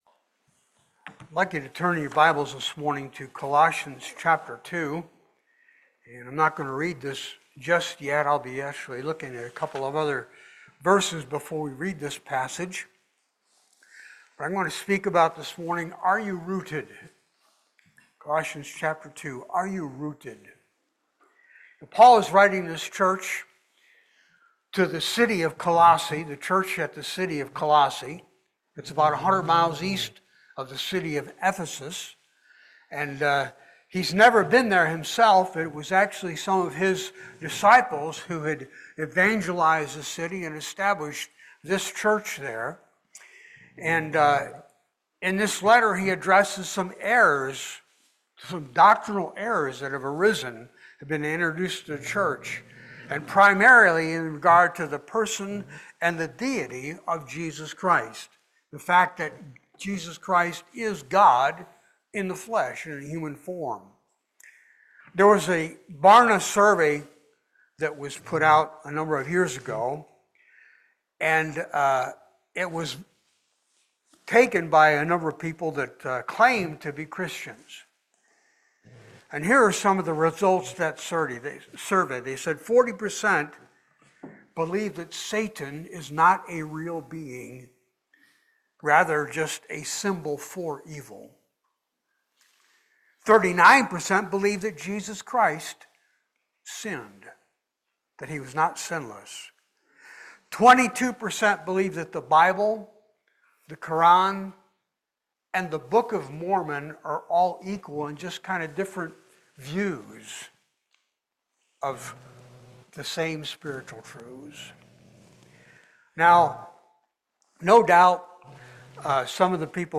Passage: Colossians 1:12 Service Type: Sunday Worship